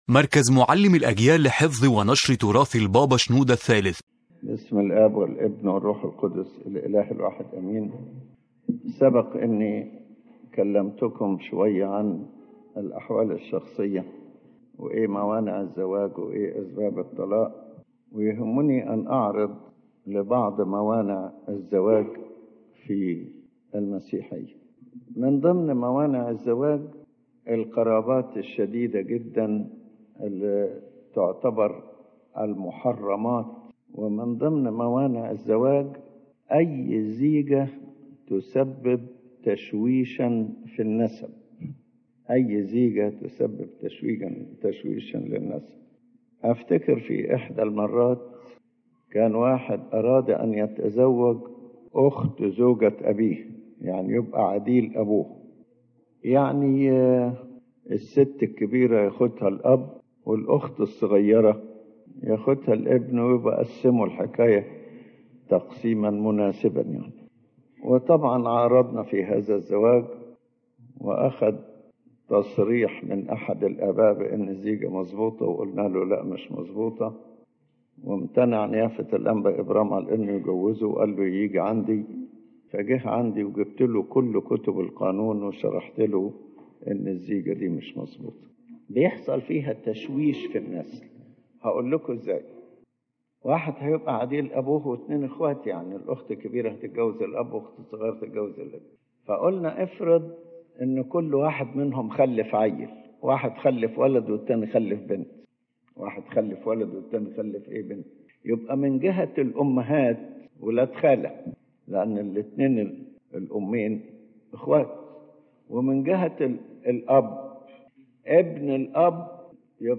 تتناول المحاضرة شرح بعض موانع الزواج في المسيحية من منظور كتابي وكنسي، مؤكدة أن هذه الموانع ليست قوانين بشرية أو مدنية، بل هي وصايا إلهية ثابتة في الكتاب المقدس، ومدعومة بقوانين المجامع الكنسية وتعاليم الآباء القديسين.